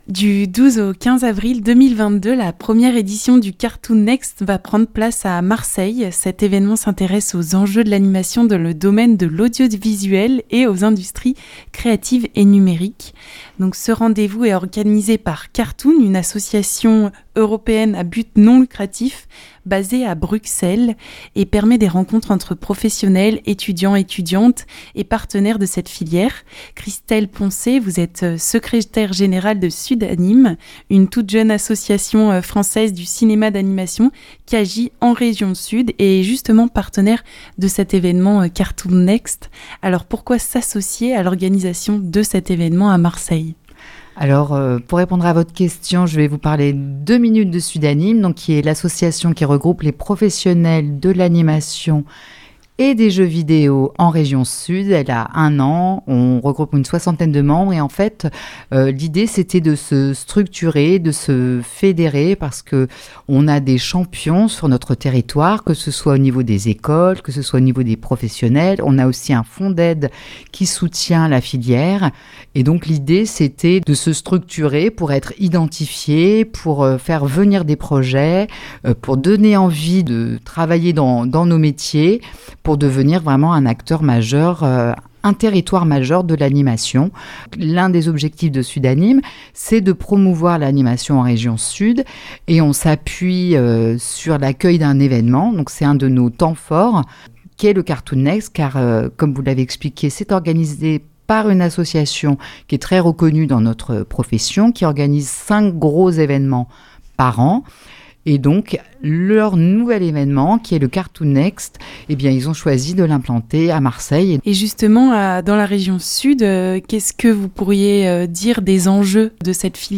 Interview sur Cartoon Next (9.27 Mo)